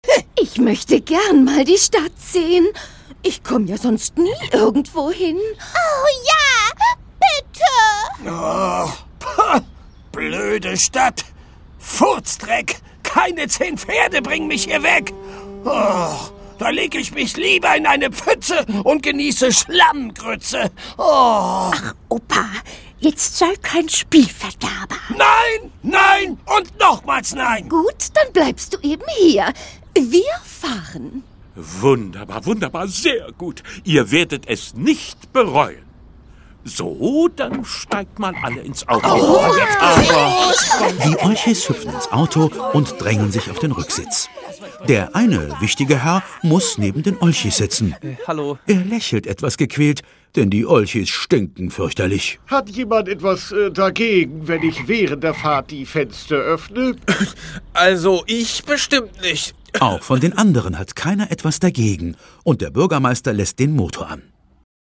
Szenische Lesung
Szenische Lesung mit den beliebten Olchi-Sprechern